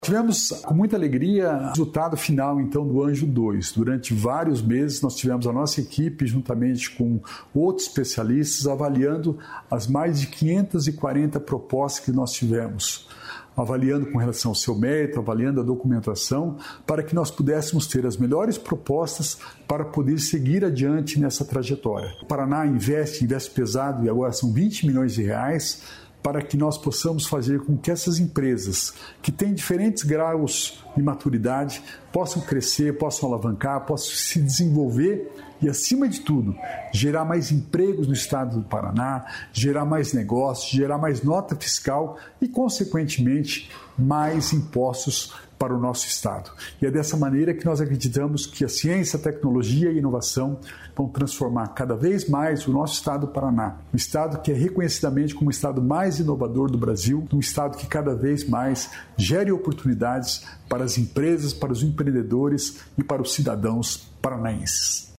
Sonora do secretário da Inovação, Modernização e Transformação Digital, Alex Canziani, sobre o Paraná Anjo Inovador